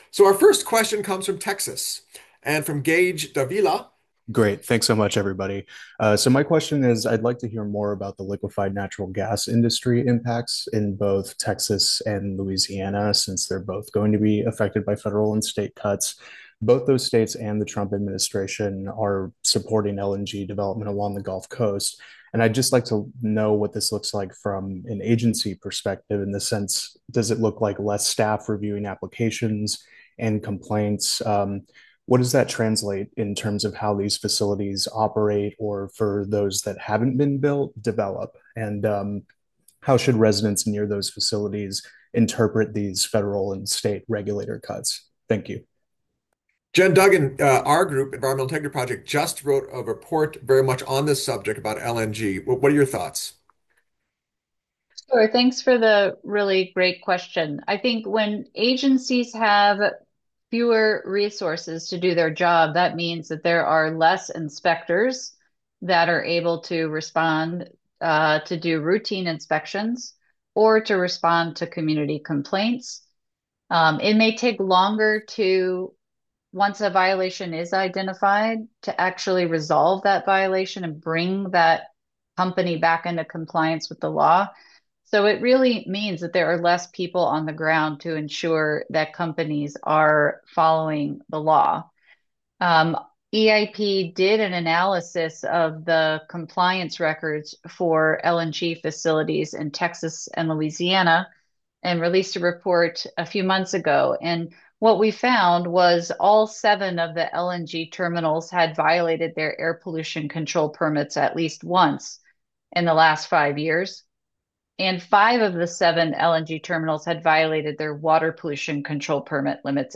For audio of the press conference, click here and here.